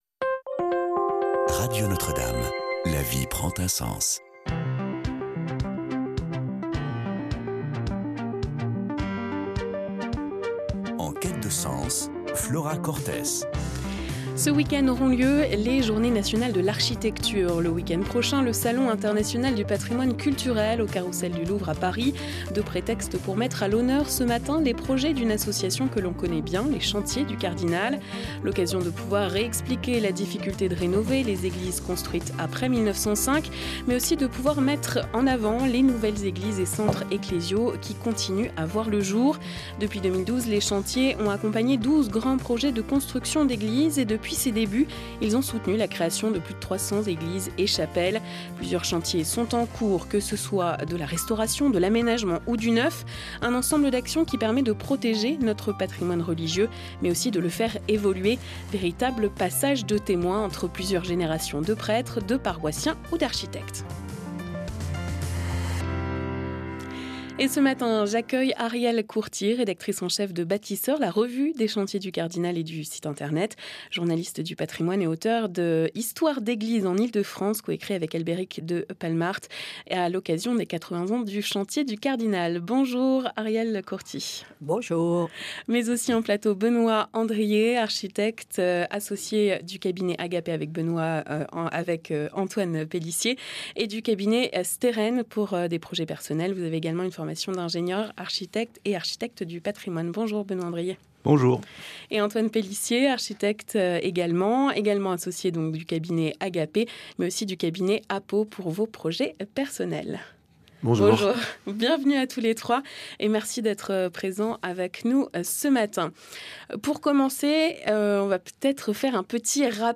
diffusée en direct